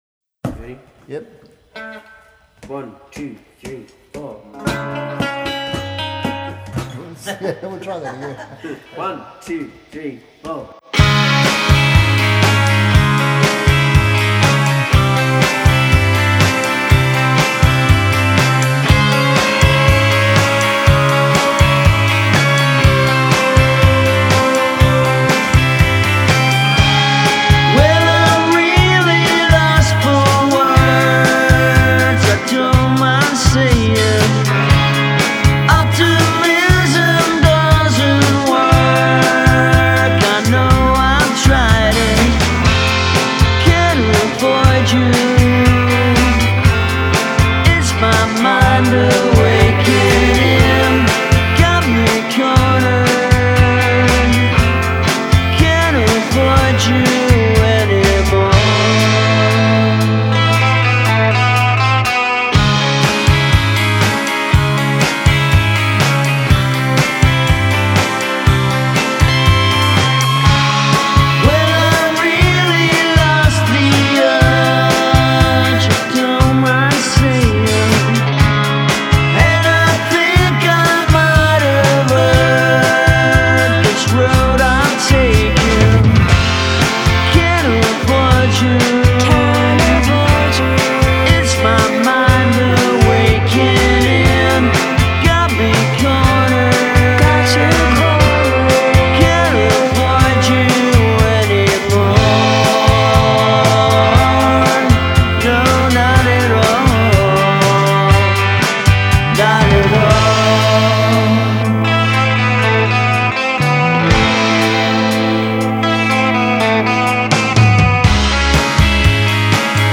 melody shift